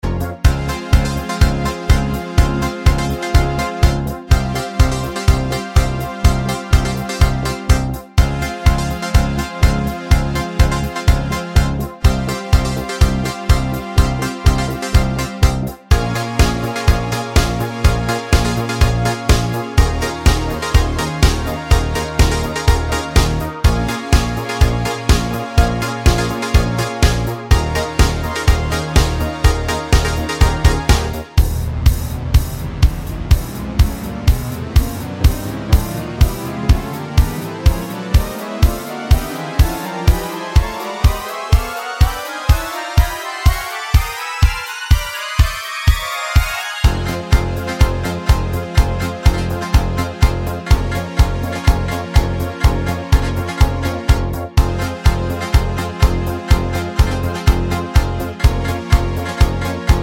no Backing Vocals Dance 4:02 Buy £1.50